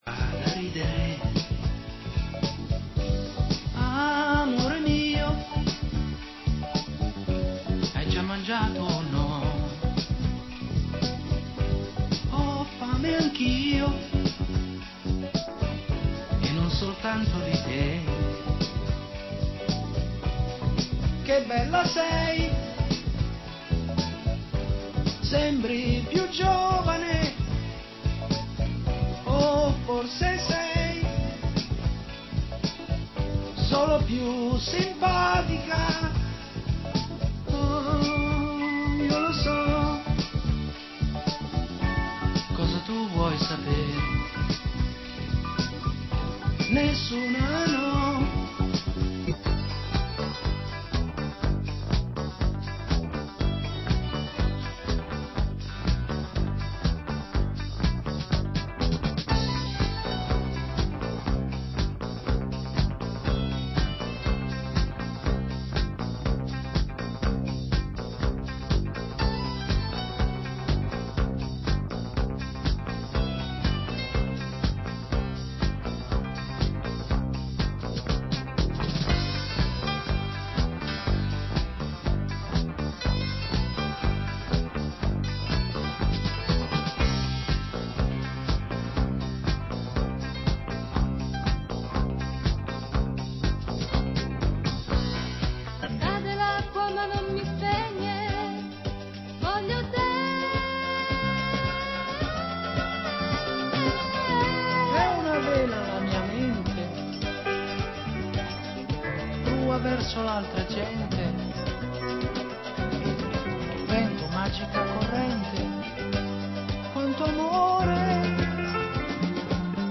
Genre: Balearic